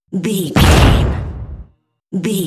Cinematic drum hit trailer
Sound Effects
Atonal
heavy
intense
dark
aggressive